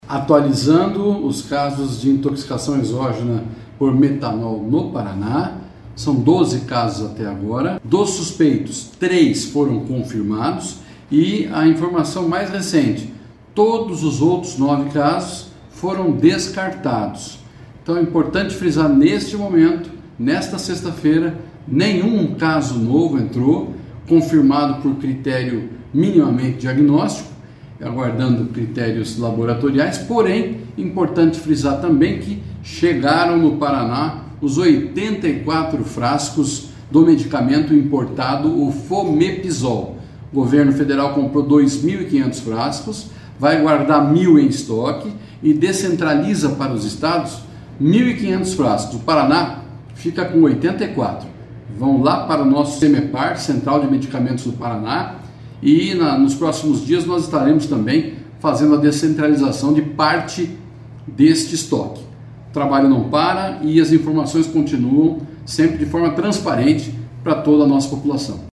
Ouça o que diz o secretário de Saúde do Paraná, Beto Preto: